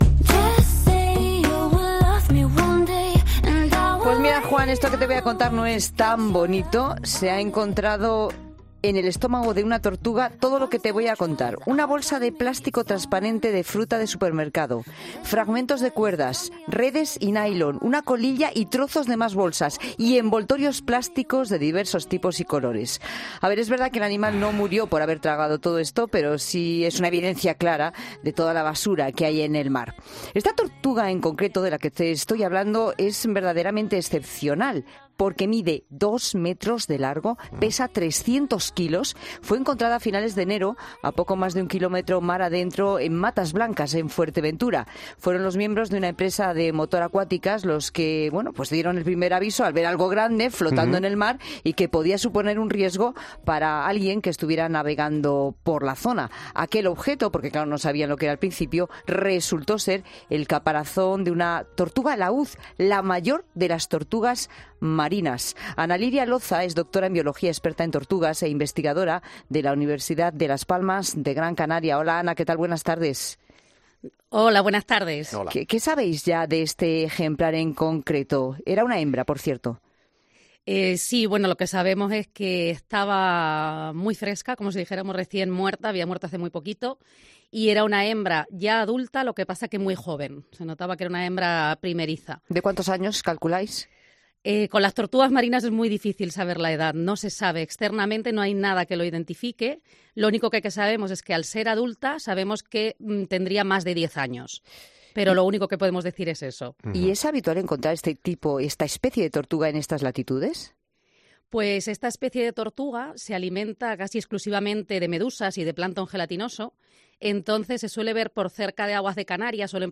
Nos cuenta que lo que se encontraron en el estómago del animal es a consecuencia de que este tipo de tortugas "se alimentan de medusas y las bolsas se parecen a ellas".